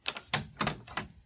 window-closing.wav